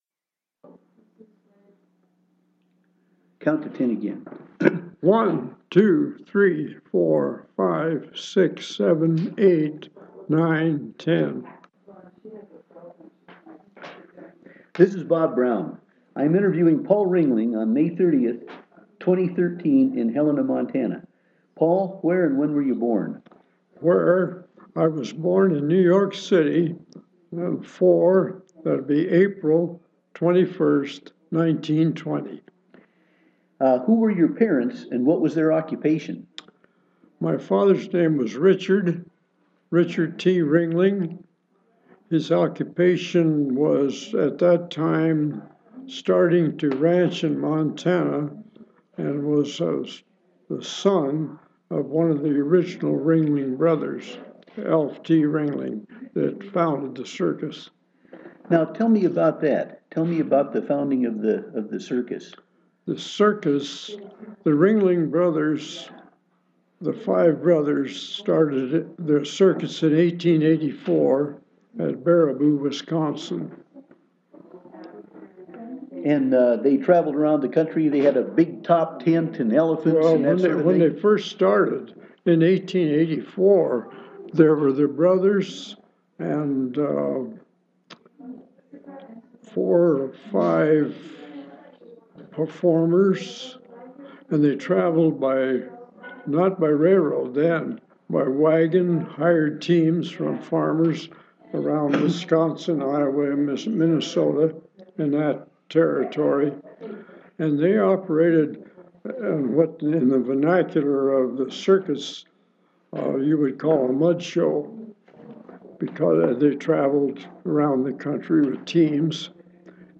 Media is loading Document Type Oral History Subjects Helena, Montana; Ringling Bros.